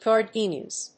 /gɑˈrdinjʌz(米国英語), gɑ:ˈrdi:njʌz(英国英語)/